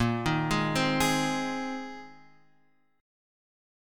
A#9sus4 chord